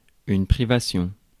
Ääntäminen
IPA: /pʁi.va.sjɔ̃/